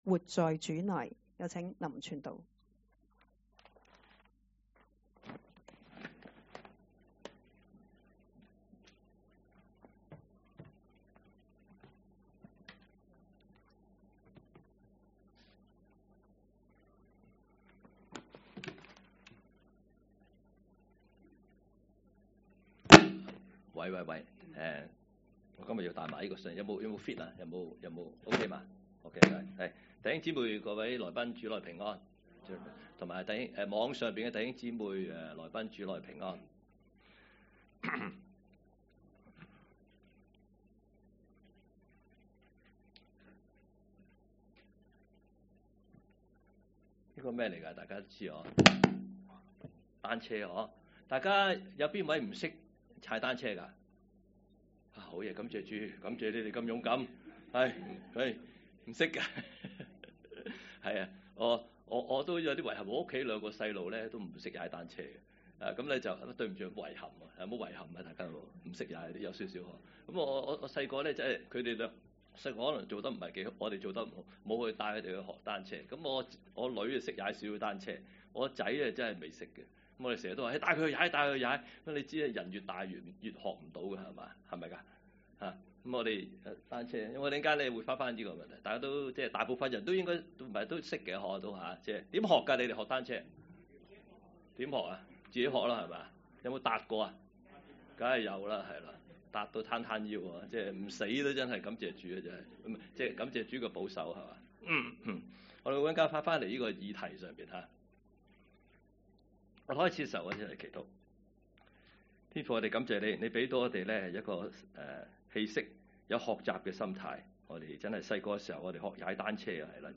2024年12月8日講道